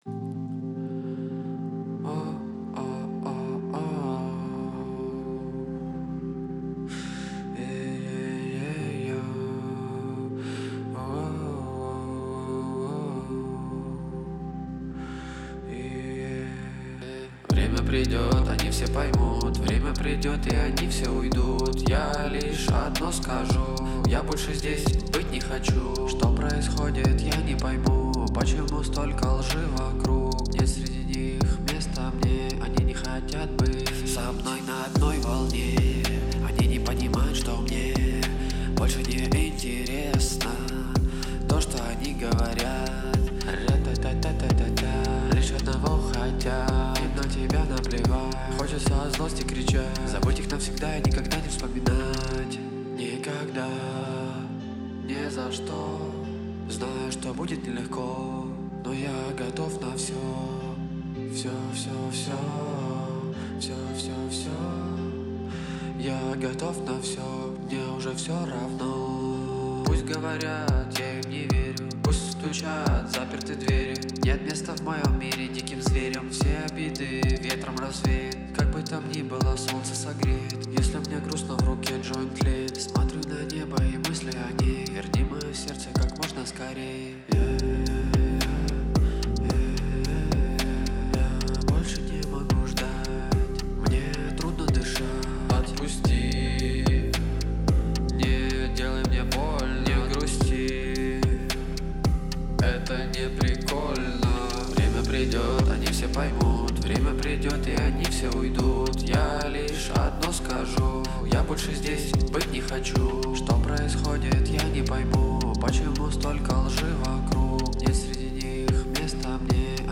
Запись вокала без перезаписи, с первого раза, не судите строго, Без Ловушек и экранов, делаю всё очень быстро, есть ещё видео , но Лимит.
Трудно Дышать.mp3 как бы глупо это не звучало , у меня был насморк ))) есть ещё много песен , разных жанров , включая клубные и реп.